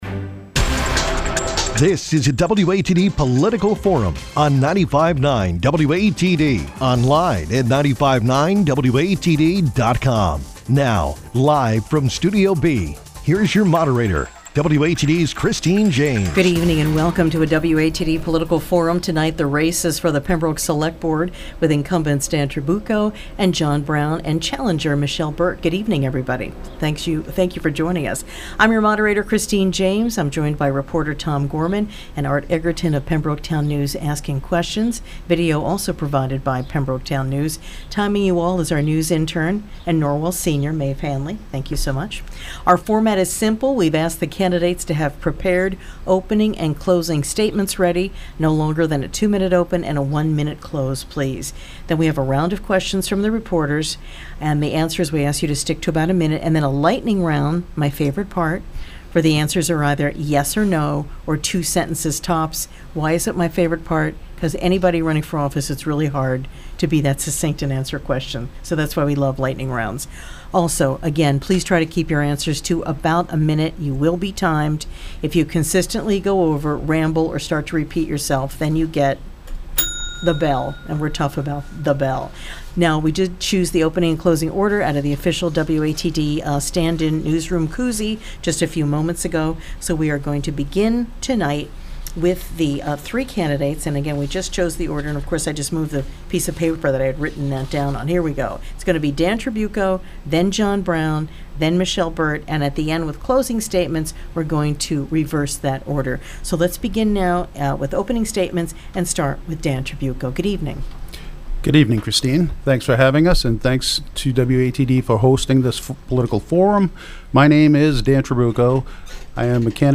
Pembroke Select Board Candidates Take Part In WATD Forum | WATD 95.9 FM